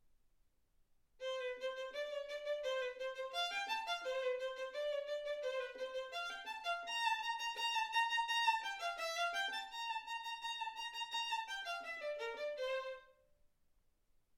Hegedű etűdök Kategóriák Klasszikus zene Felvétel hossza 00:14 Felvétel dátuma 2025. december 8.